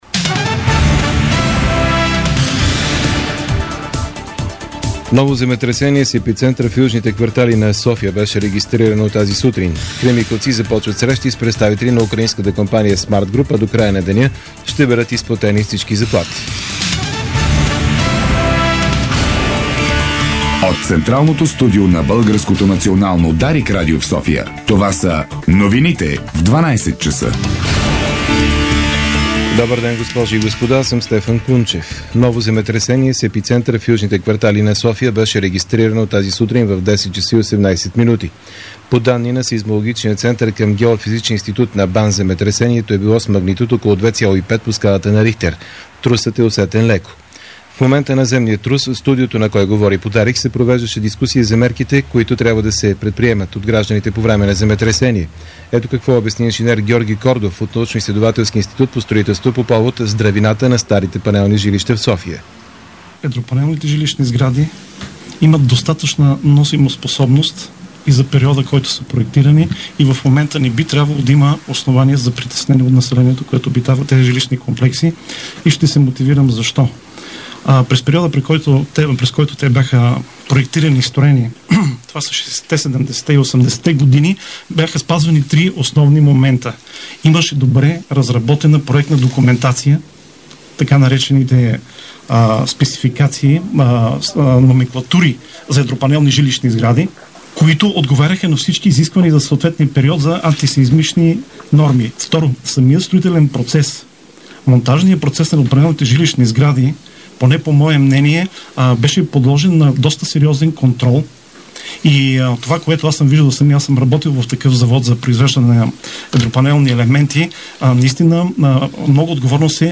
Обедна информационна емисия - 18.11.2008